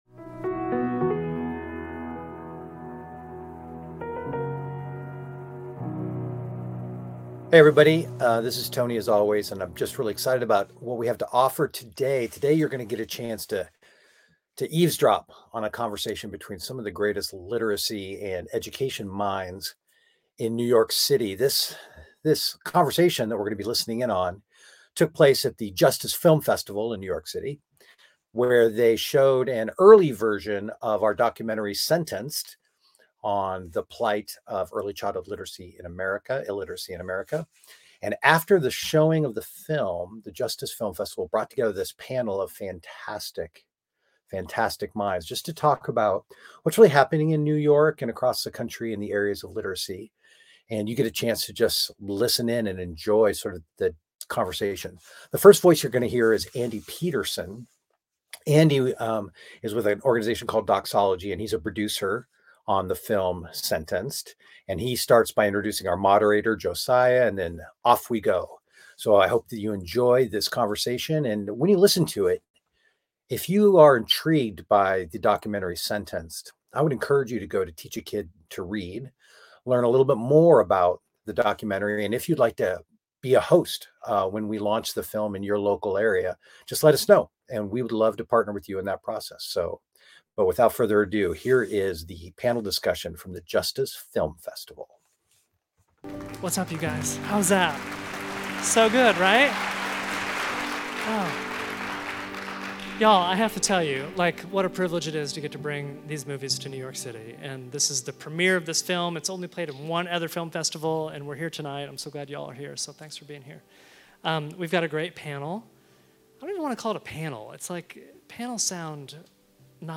Enjoy this discussion about childhood illiteracy from a panel of experts from New York City.